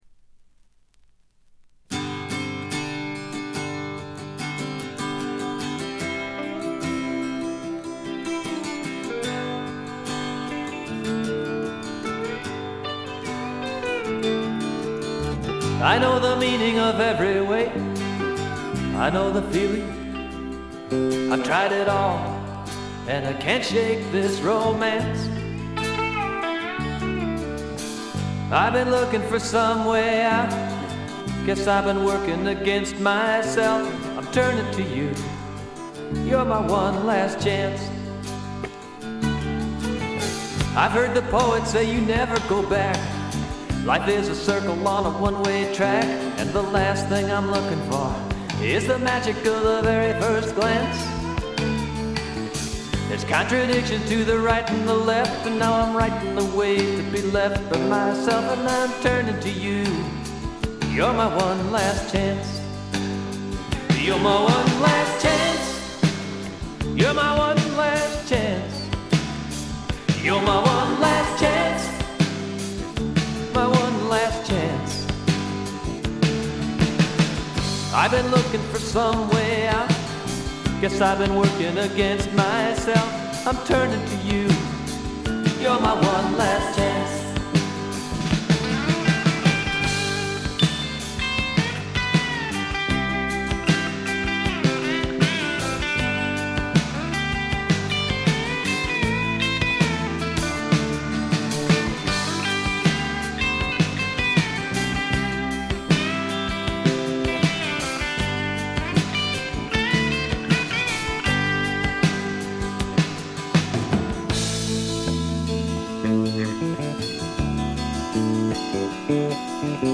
部分試聴ですがほとんどノイズ感無し。
さて内容は85年という時代を感じさせないフォーキーな好盤に仕上がっております。
試聴曲は現品からの取り込み音源です。